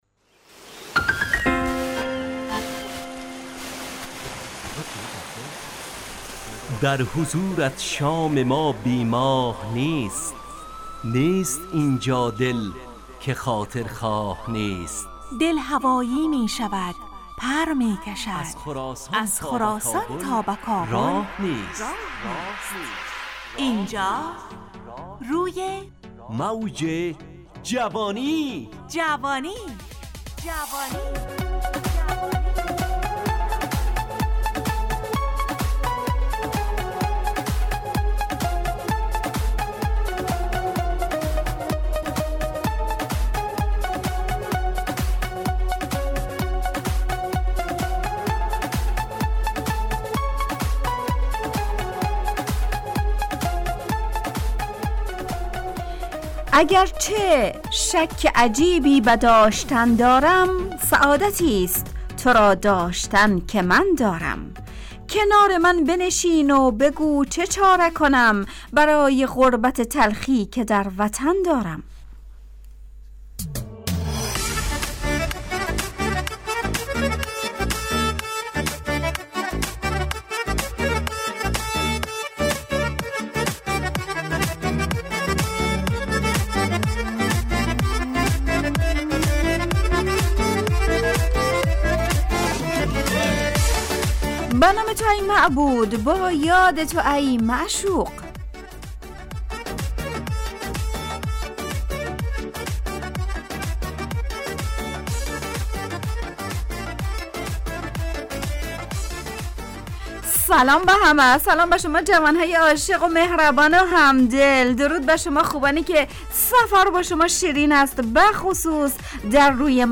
برنامه شادو عصرانه رادیودری
همراه با ترانه و موسیقی مدت برنامه 55 دقیقه . بحث محوری این هفته (هنر) تهیه کننده